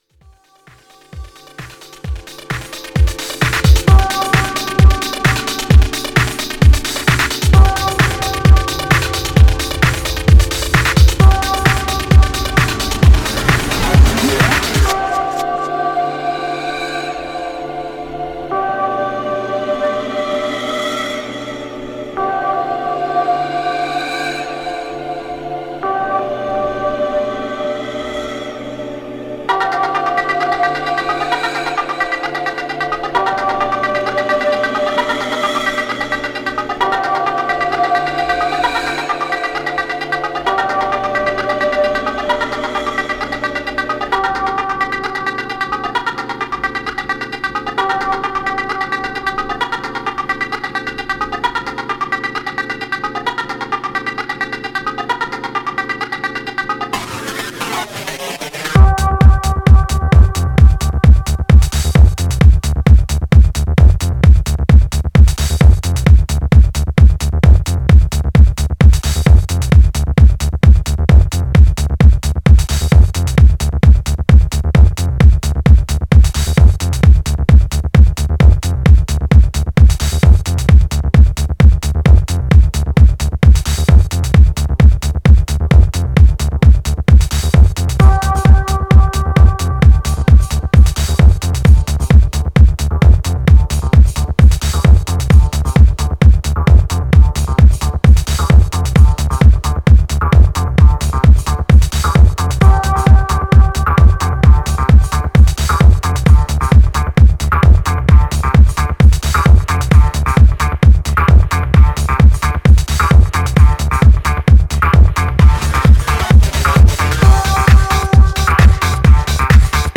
Styl: Progressive, House, Breaks/Breakbeat